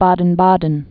(bädn-bädn)